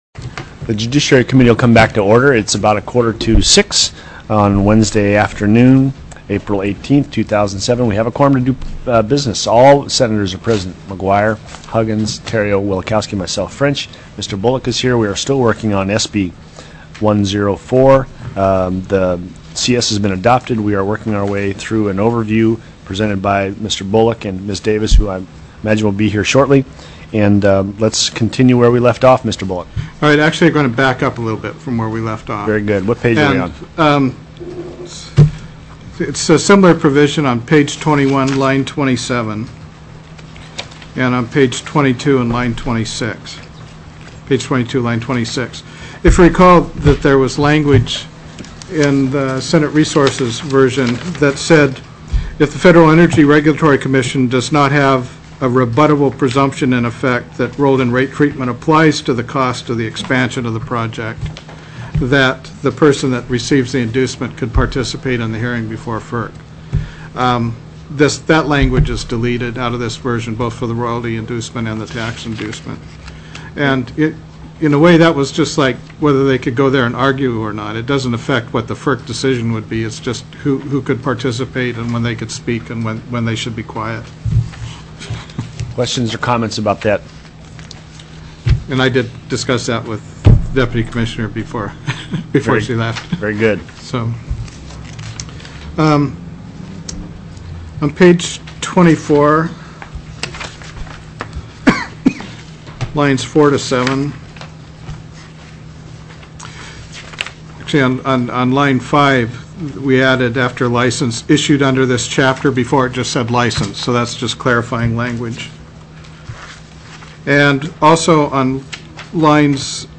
SB 104 NATURAL GAS PIPELINE PROJECT TELECONFERENCED